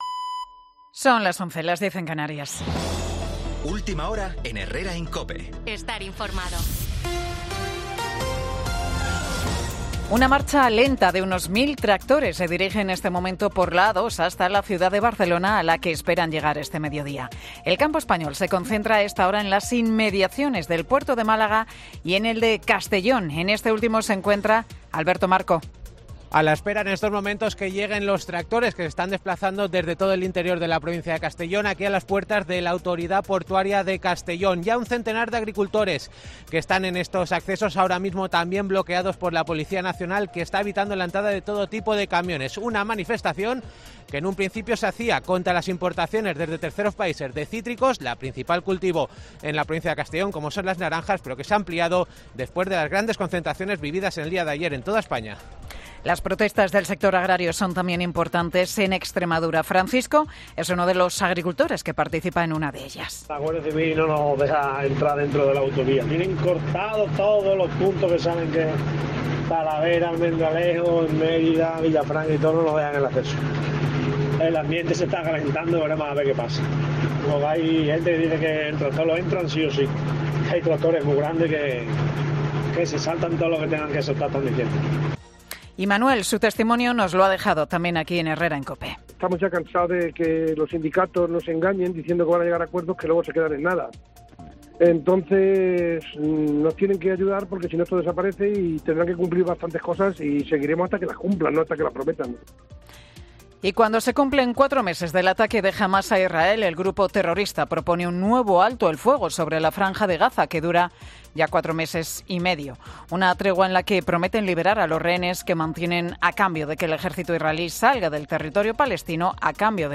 Boletín de Noticias de COPE del 7 de febrero del 2024 a las 11 horas